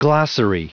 Prononciation du mot : glossary